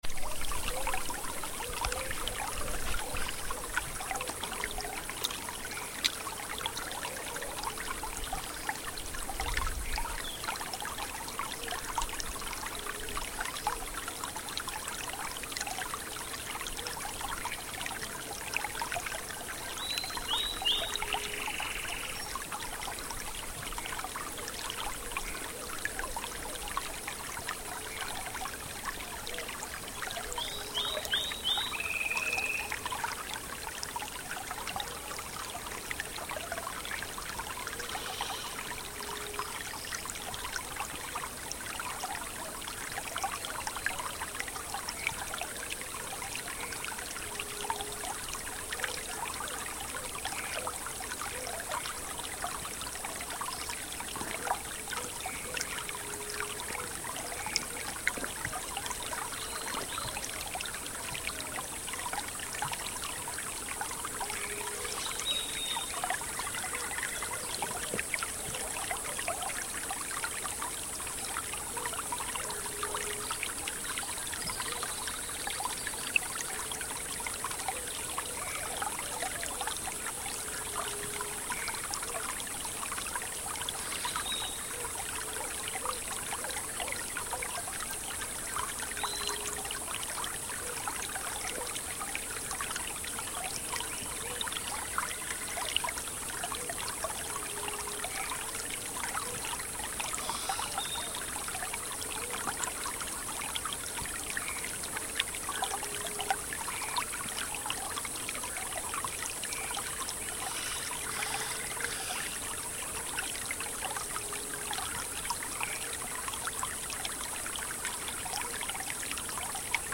Como podrán observar en las fotografías el río se encuentra muy saludable en cuanto a su diversidad biológica, de el emana una vertiente de agua que se conecta con el flujo principal del río es de esta que ustedes escucharán el sonido del agua.
9:00 horas Lugar: Río La Lombricera, Pacú. Suchiapa, Chiapas Mexico. Equipo: Micrófonos binaurales de construcción casera, si desea construir los suyos aquí encuentra las instrucciones . Grabadora Sony ICD-UX80 Stereo.